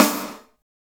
Index of /90_sSampleCDs/Roland L-CD701/SNR_Snares 2/SNR_Sn Modules 2
SNR PICC 02L.wav